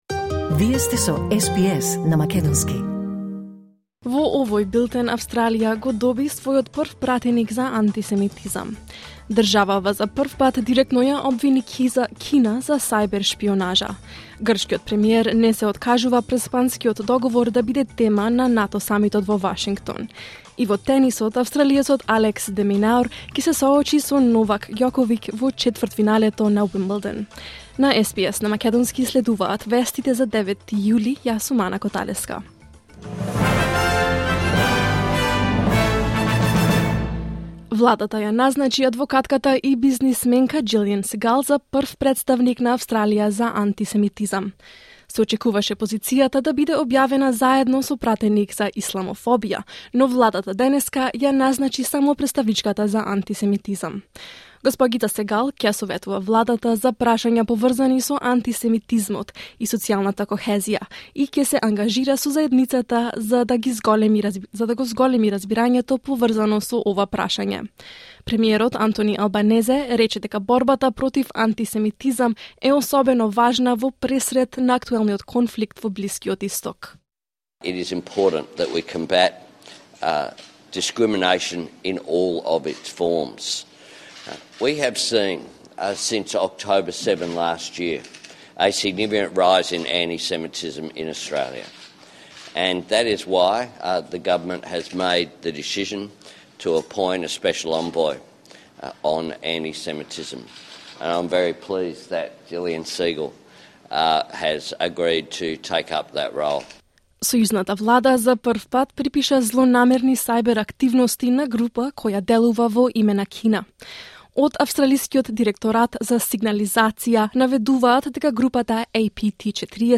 SBS News in Macedonian 9 July 2024